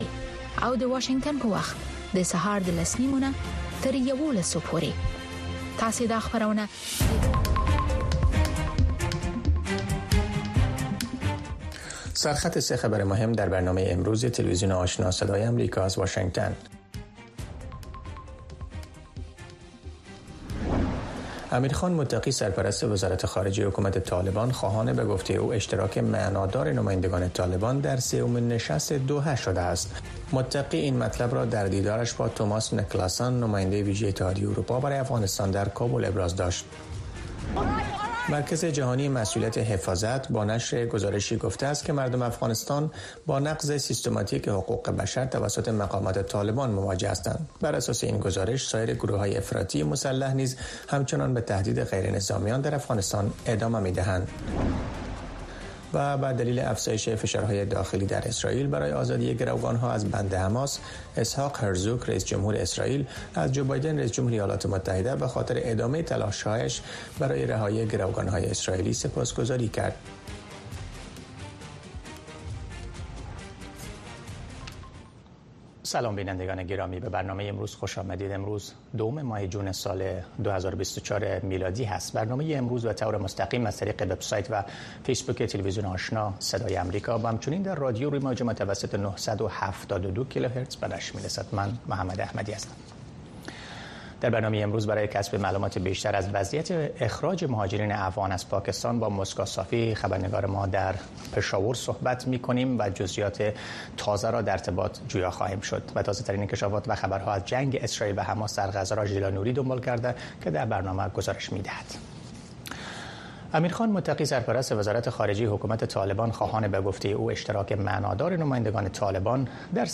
برنامه خبری آشنا